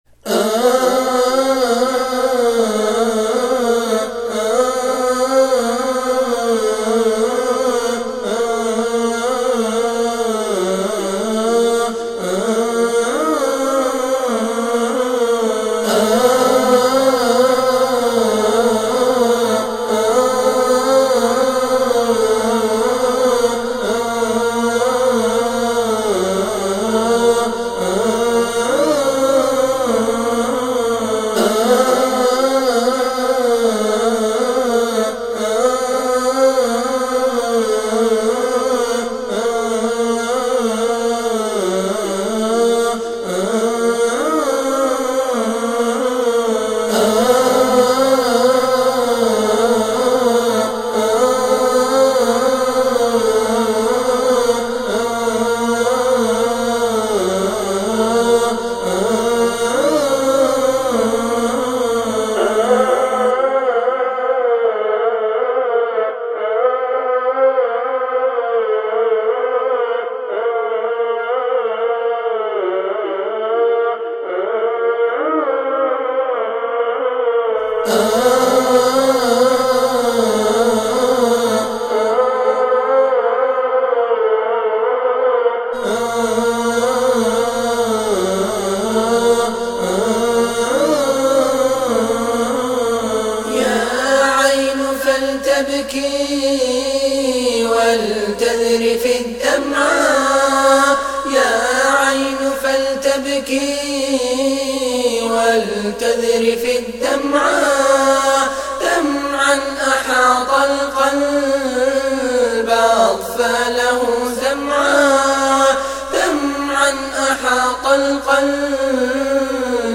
( يا عين فلتبكي و لتذرفي الدمع ) ، نشيد جديد .
أولا .. الاهات كثيرة ..
ثانيا .. المنشد .. متكلف ..
اللحن جميل .. ذكرني .. بالأناشيد القديمة